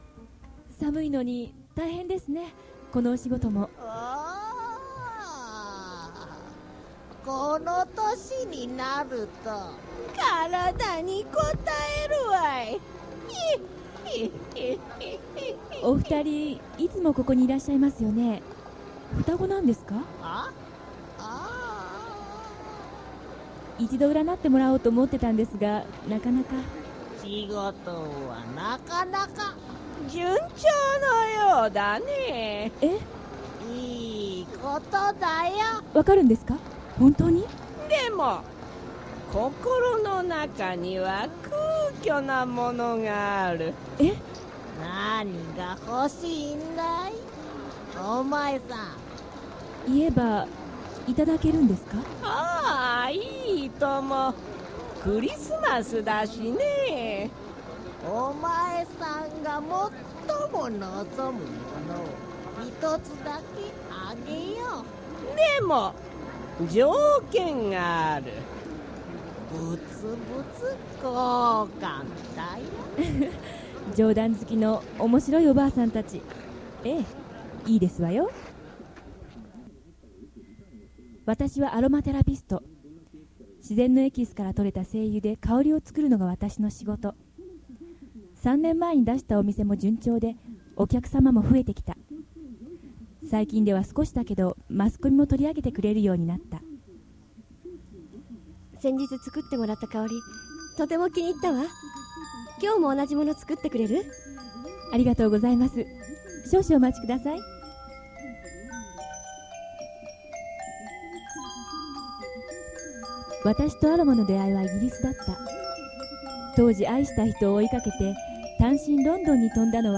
ラジオドラマ「自選式幸福」 | at23:00